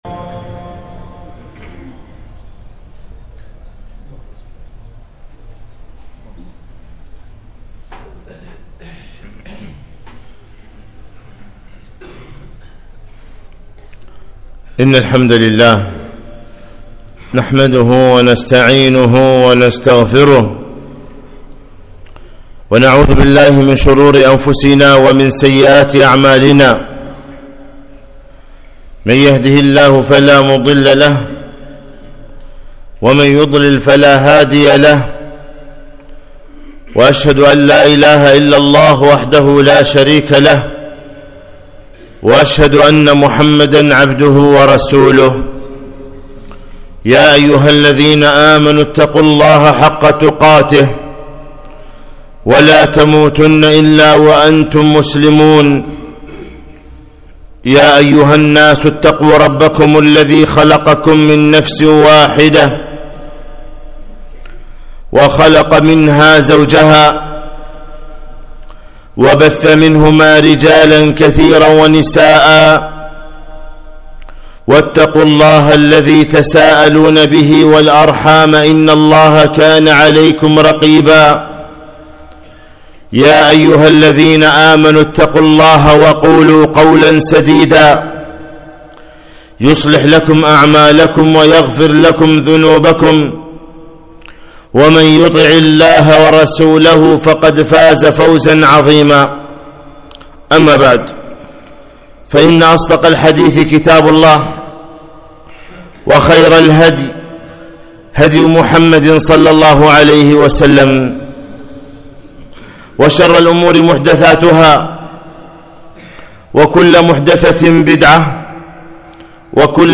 خطبة - نحن قوم أعزنا الله بالإسلام